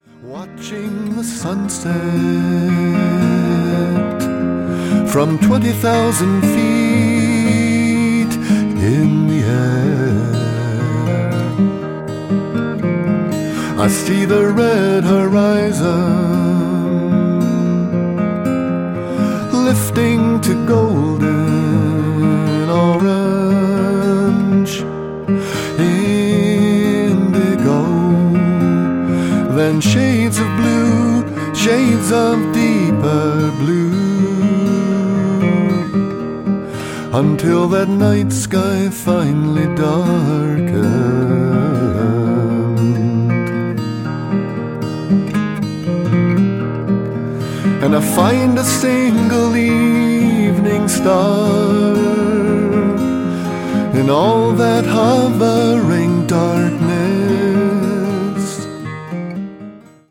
guitar, tenor guitar